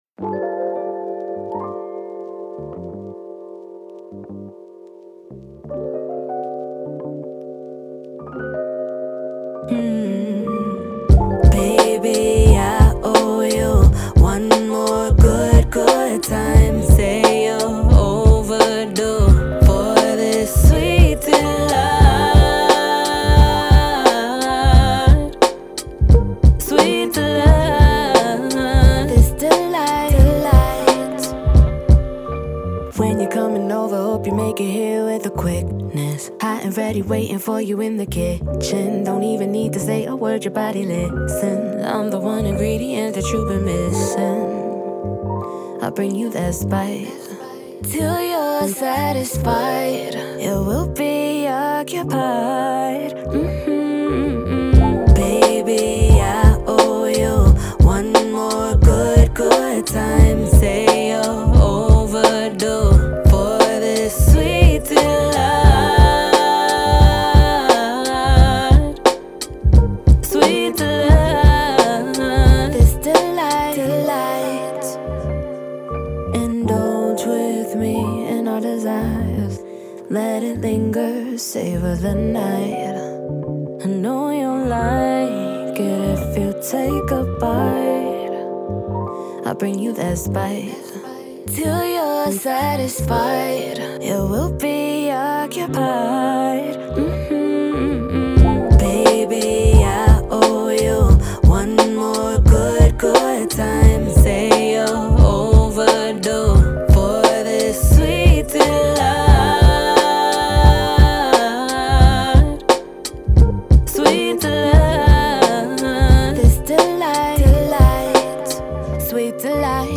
R&B
D min